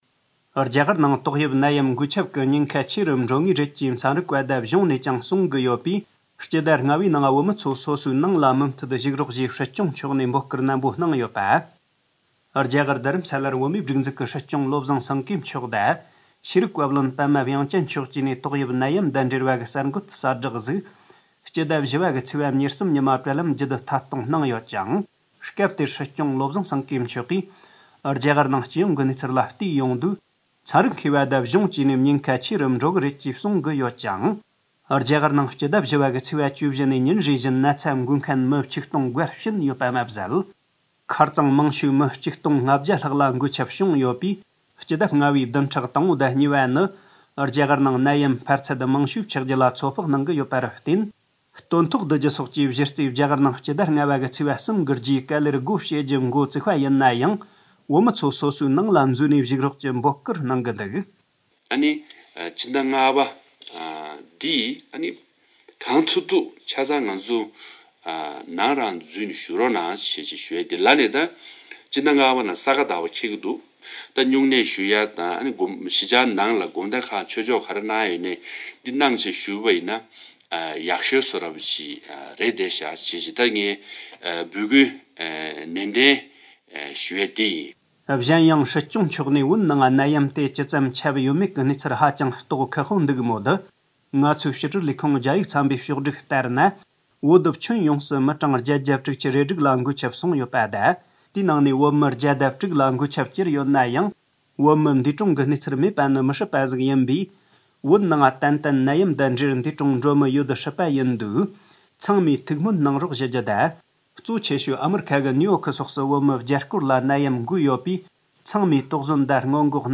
སྲིད་སྐྱོང་མཆོག་ནས་ཏོག་དབྱིབས་ནད་ཡམས་སྔོན་འགོག་ཆེད་རང་ཁྱིམ་ནང་མུ་མཐུད་བཞུགས་དགོས་པའི་འབོད་སྐུལ་གནང་བ། སྲིད་སྐྱོང་བློ་བཟང་སེངྒེ་མཆོག་གསར་འགོད་ལྷན་ཚོགས་ཤིག་གི་སྐབས།
སྒྲ་ལྡན་གསར་འགྱུར།